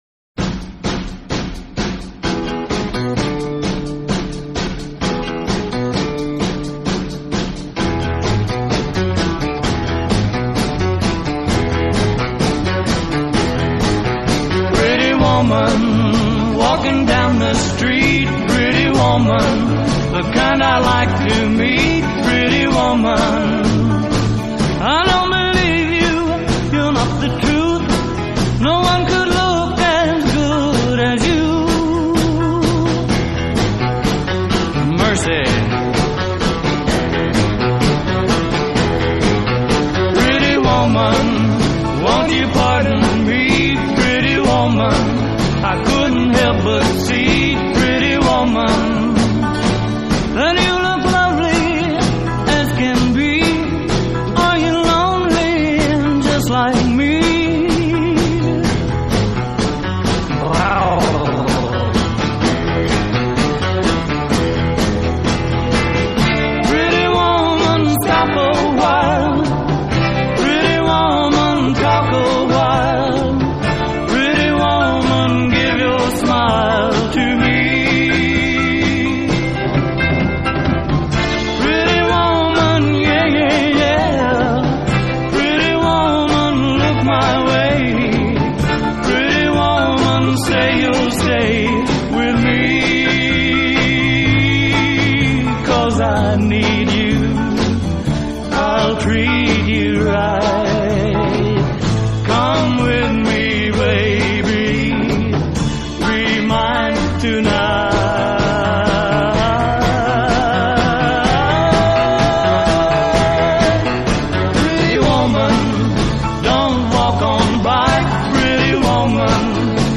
Rock and Roll, Pop